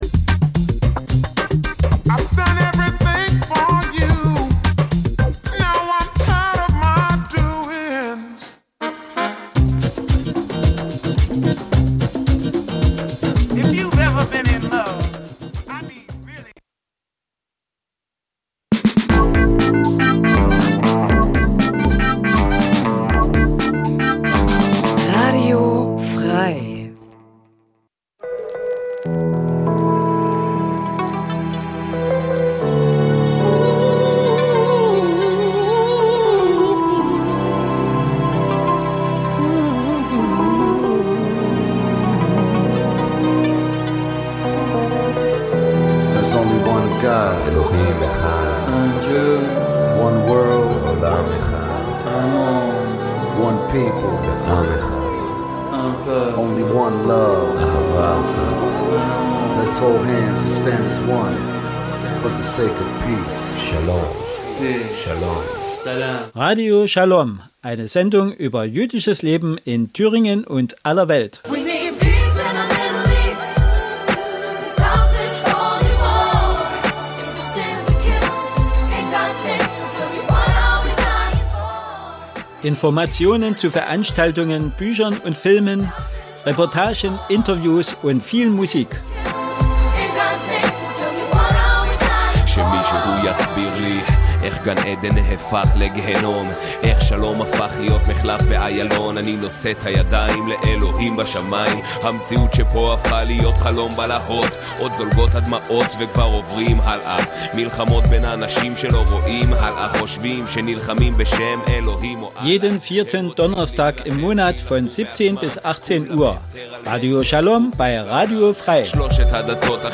Radio Schalom berichtet �ber j�disches Leben in Deutschland, Diskussionen �ber Tradition und Religion, Juden in Israel und in der Diaspora sowie Musik aus Israel und anderen Teilen der Welt.